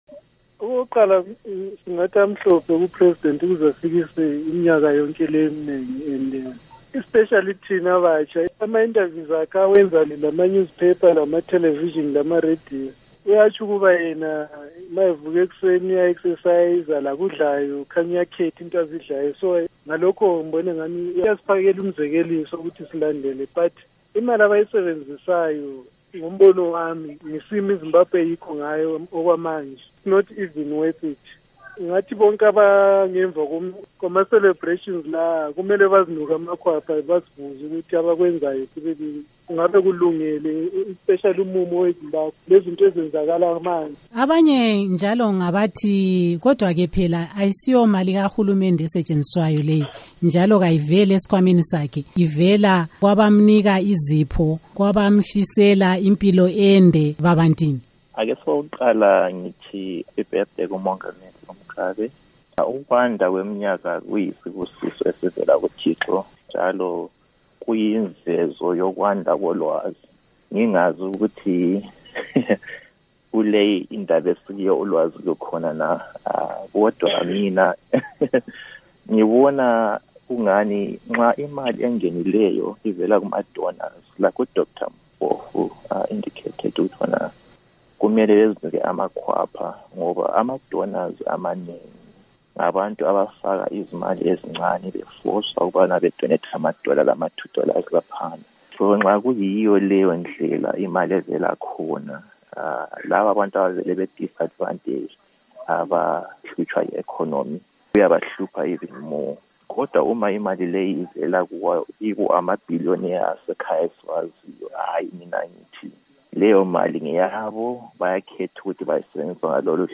Ingxoxo yokuhlaziya ukwenziwa kwedili elikhulu lokujabulela ilanga lokuzalwa kukaMugabe